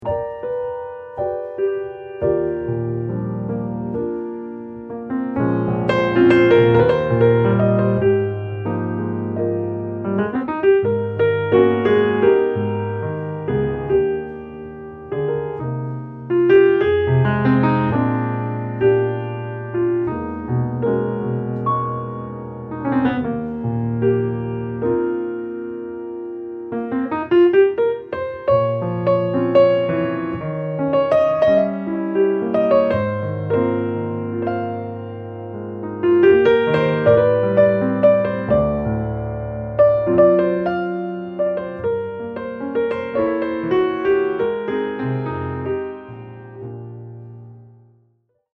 Sängerin, Saxophon, Kontrabass, Piano, Schlagzeug